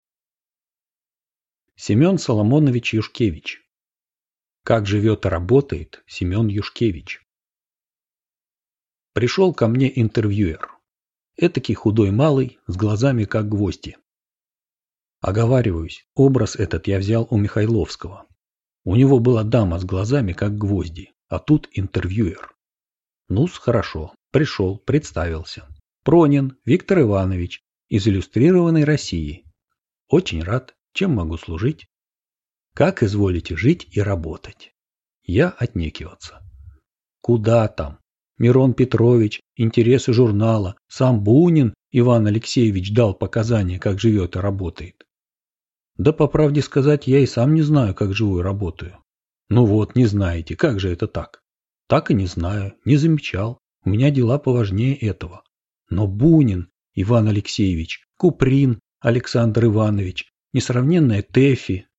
Аудиокнига Как живет и работает Семен Юшкевич | Библиотека аудиокниг